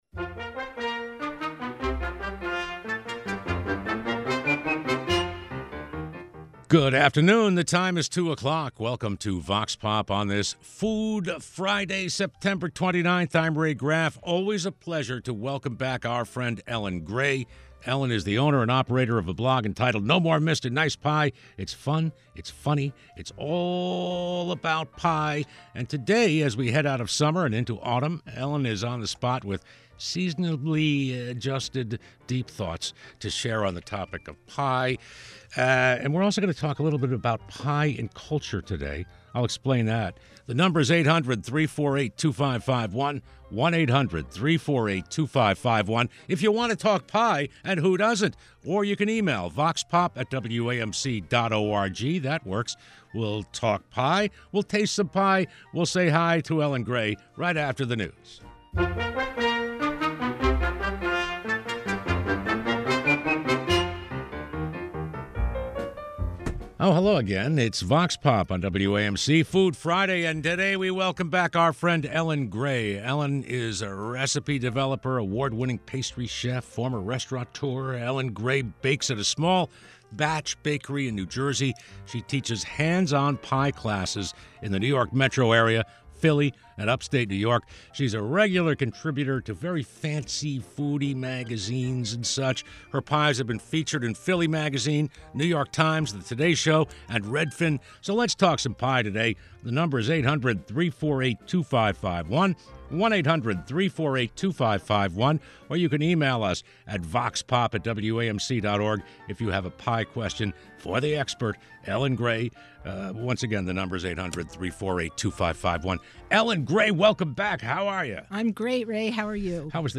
Vox Pop is WAMC's live call-in talk program.